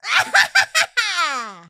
willow_kill_vo_02.ogg